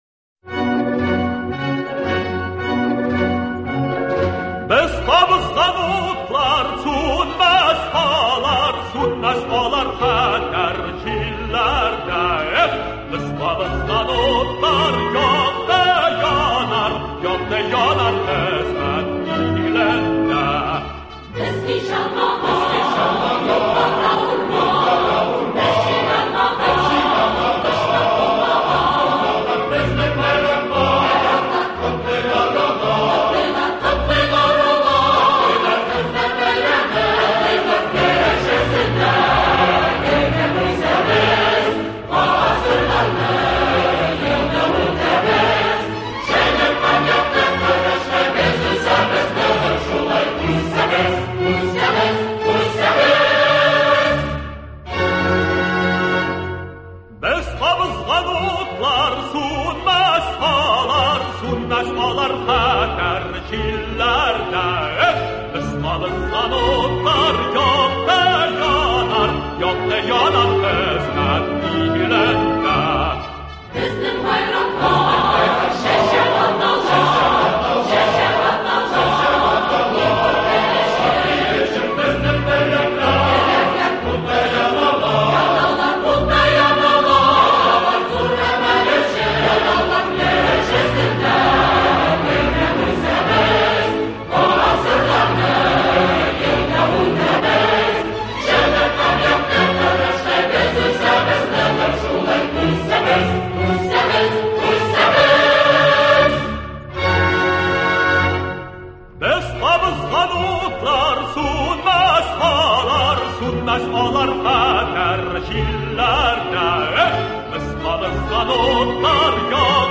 Илдар Юзеев исән чакта аның иҗатына багышлап әзерләнгән тапшыруда әңгәмәдәшләр